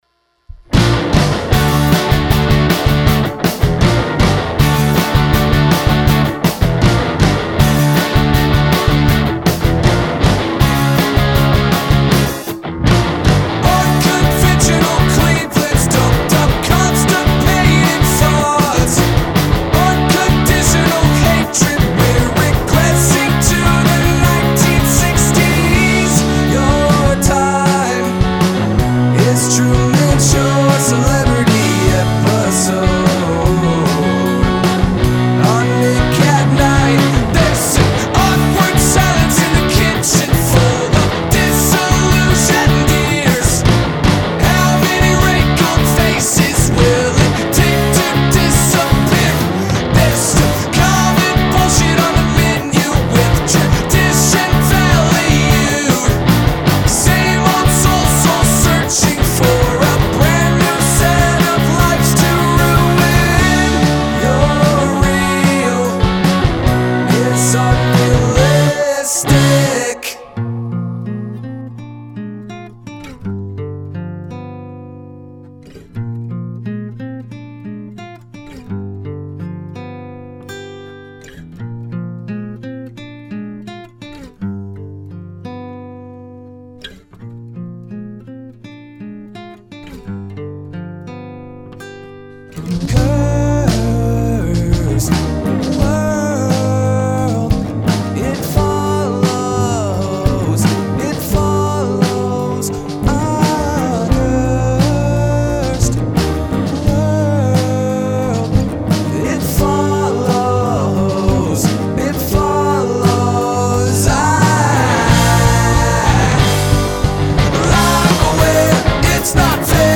VOX, Guitars, Synths, Percussion